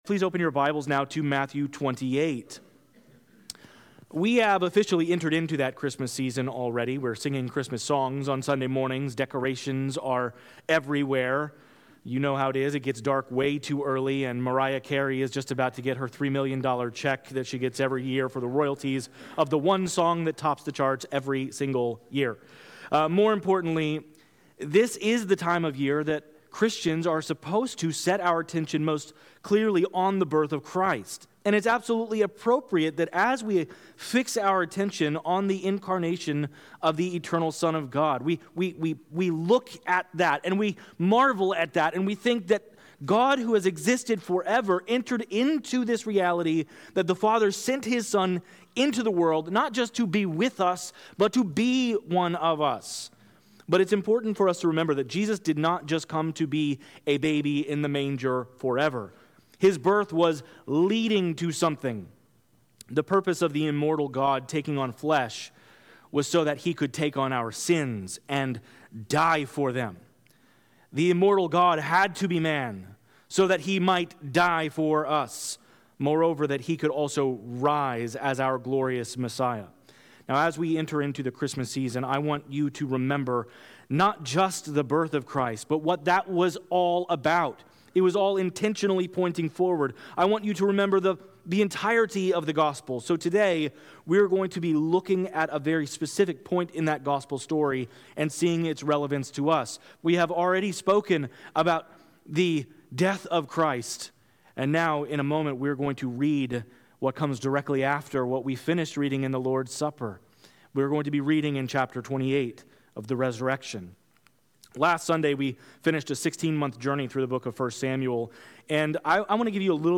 This podcast contains the sermons preached at Levittown Baptist Church in Levittown, New York.